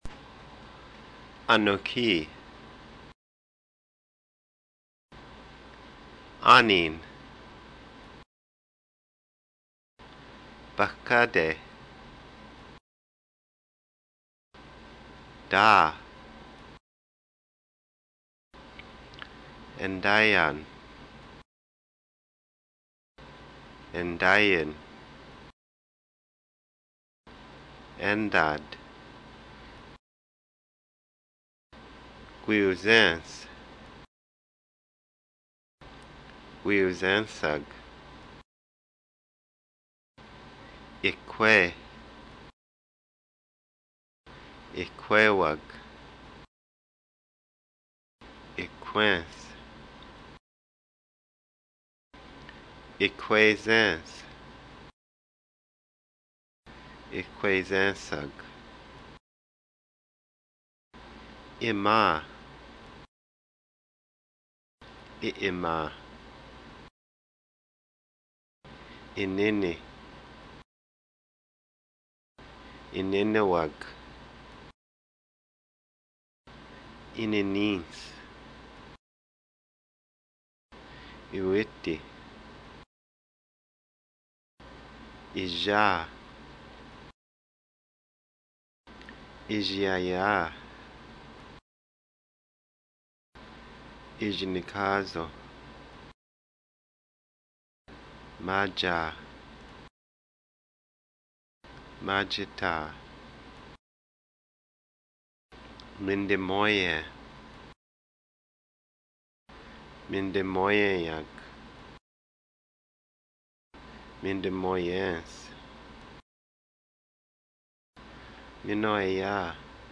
Vocabulary
I will also make an mp3 file of my own pronunciation of vocabulary for each week's vocabulary.